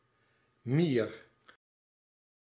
In citation forms, /r/ in the syllable coda is pronounced as a pharyngealized pre-velar bunched approximant [ɰ̟ˤ] (known in Dutch as the Gooise r) that is acoustically similar to [ɻ]: [kɛ̝ɰ̟ˤk, ˈkilömeitəɰ̟ˤ, mïə̯ɰ̟ˤ] etc. Other realizations (alveolar taps and voiced uvular fricatives) are also possible, depending on the region and individual speaker, so that mier may be also pronounced [mïə̯ɾ] or
[mïə̯ʁ].